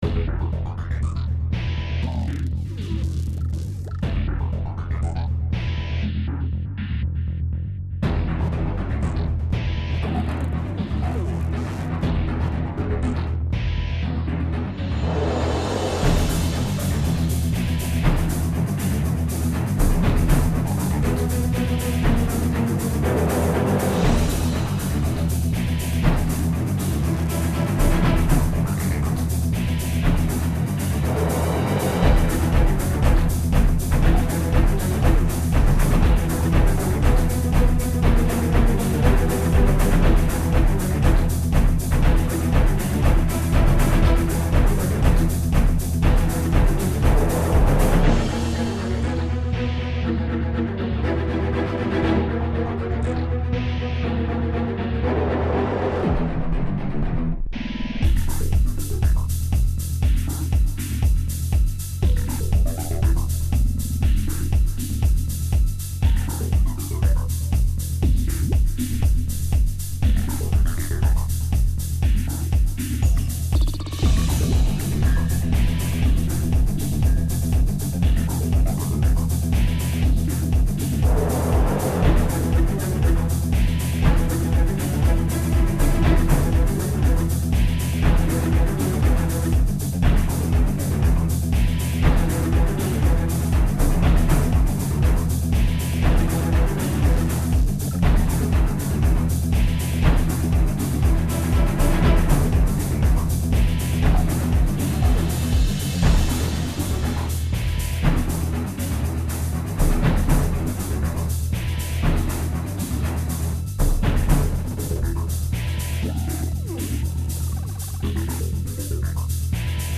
High impact dramatic film score music.
Tagged as: Electronica, Orchestral